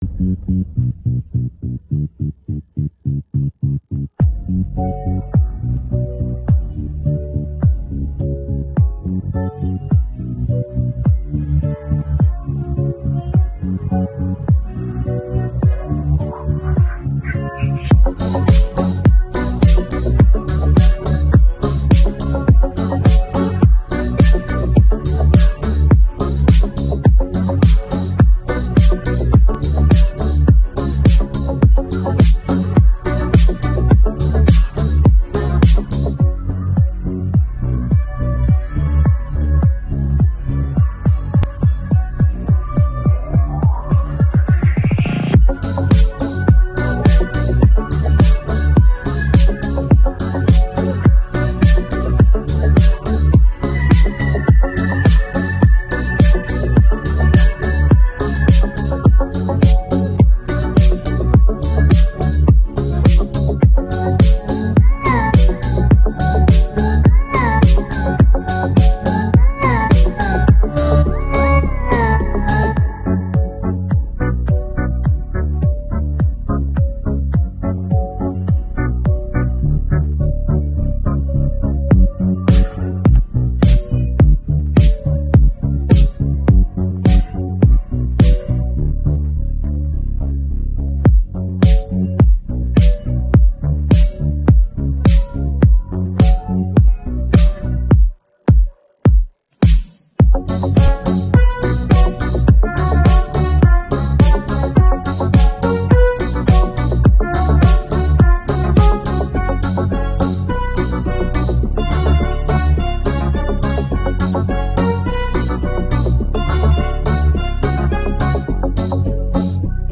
Я мінус 1
українське караоке 622
Українські хіти караоке